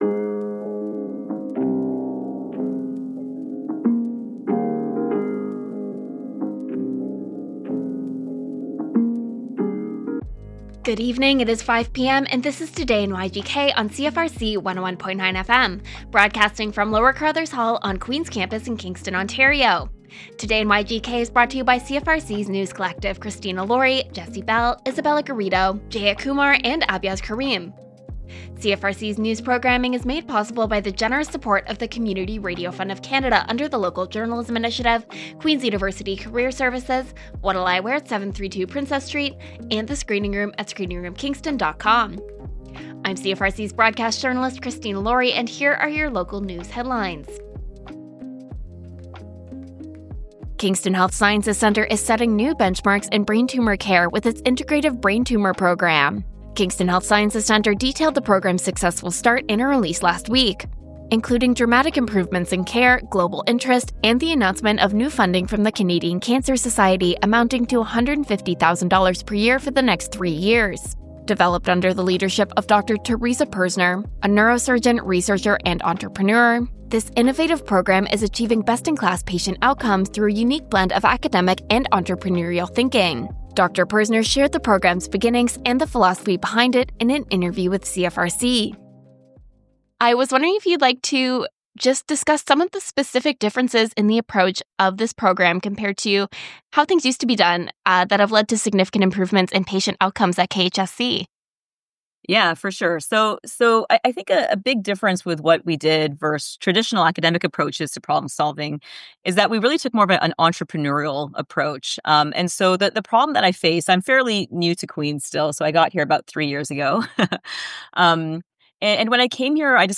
A Kingston, Ontario news program produced by the CFRC News Team features local and campus headline news, special segments, interviews, weather, sports, traffic reports and an events calendar.